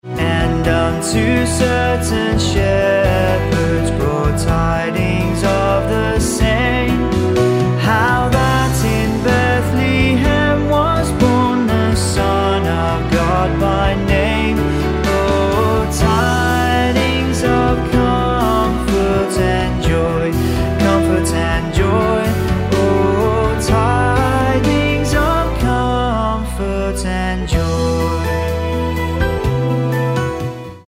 Em